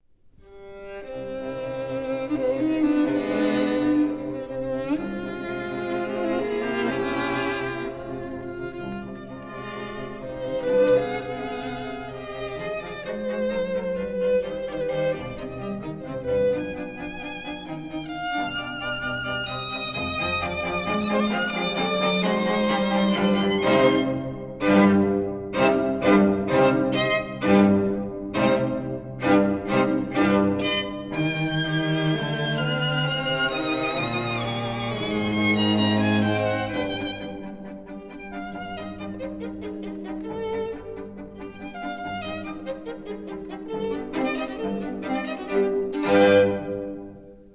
violin
viola
cello
Studios,30th Street, New York City